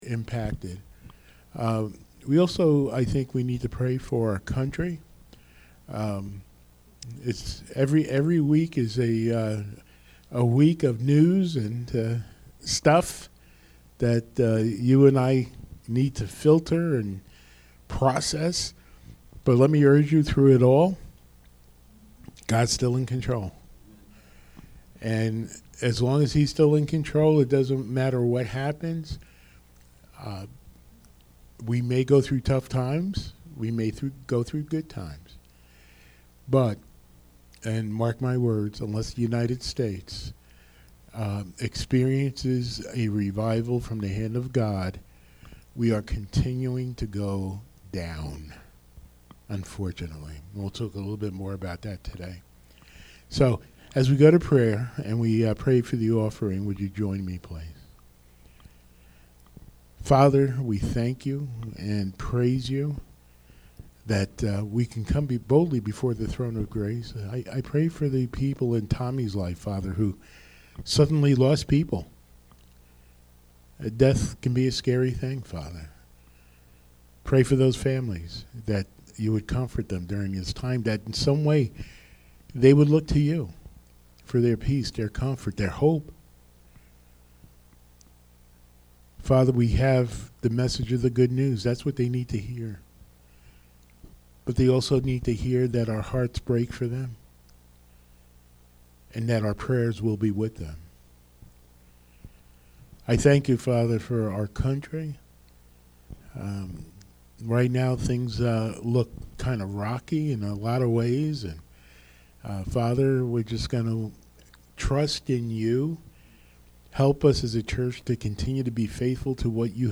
Podcast (sermons)